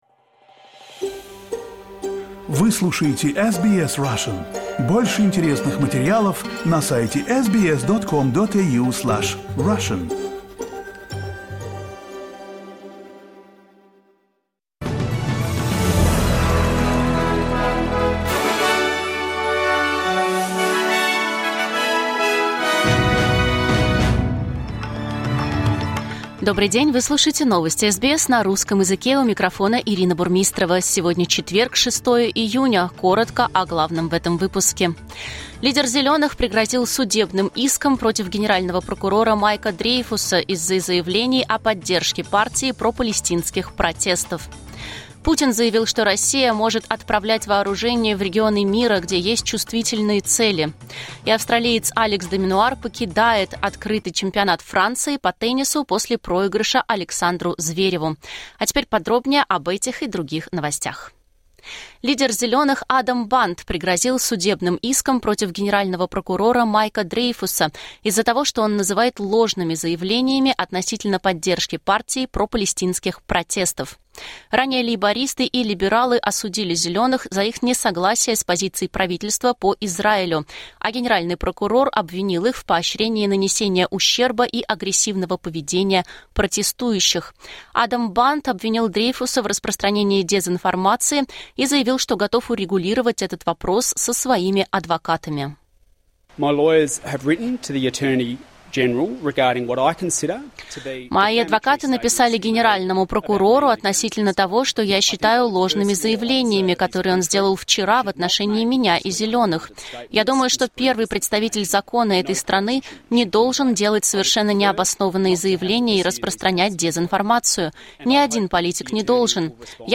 SBS News in Russian — 06.06.2024